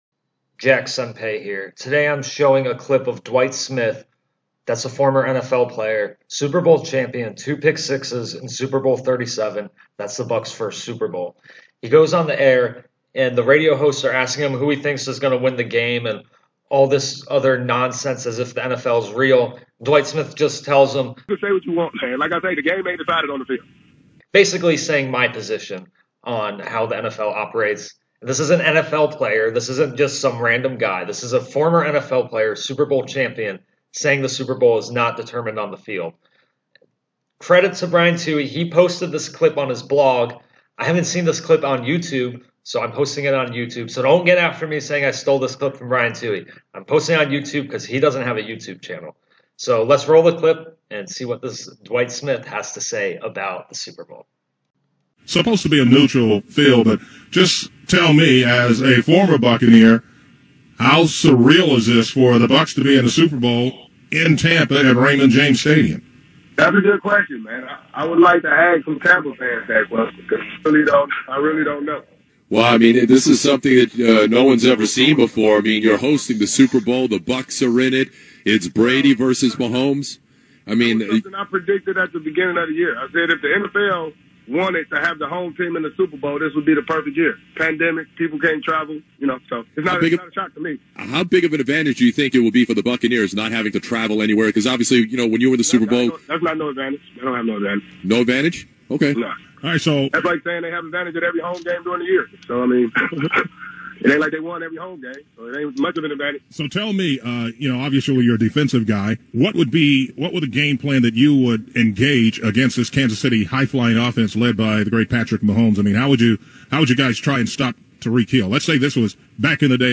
Link to full radio interview: